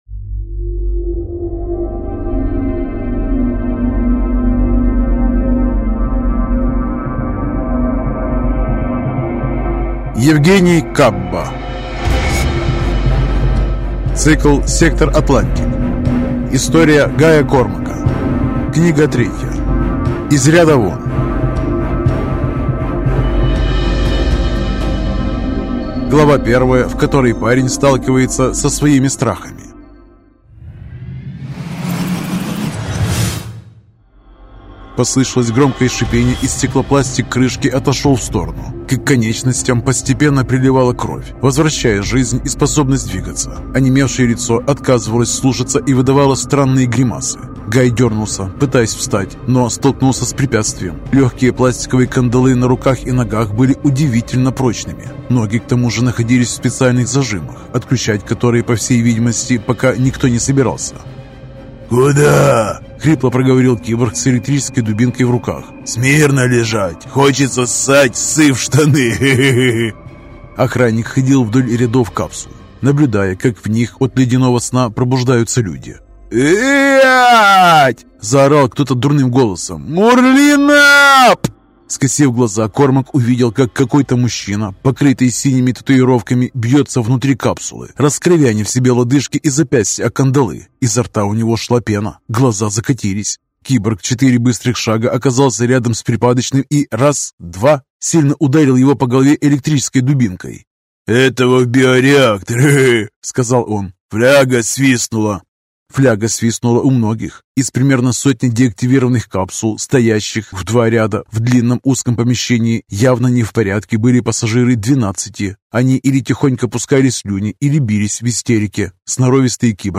Аудиокнига Из ряда вон | Библиотека аудиокниг